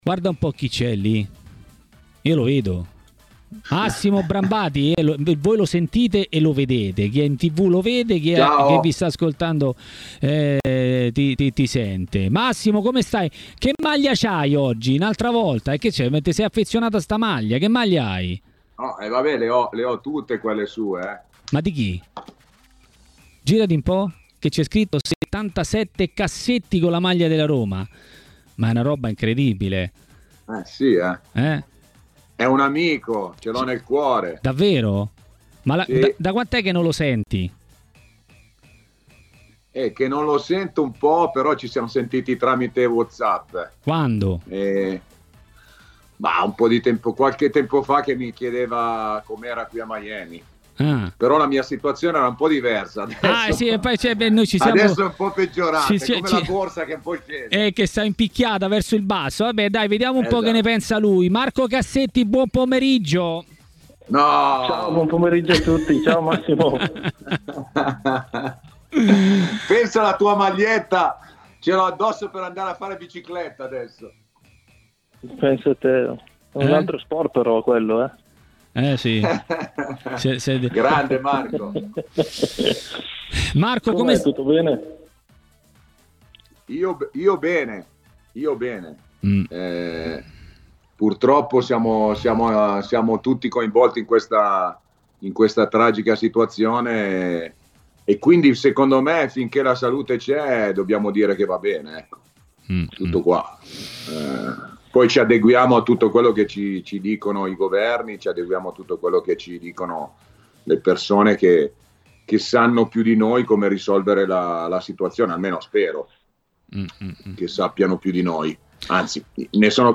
Marco Cassetti, ex calciatore, ha parlato a Maracanà, nel pomeriggio di TMW Radio, della sua esperienza alla Roma.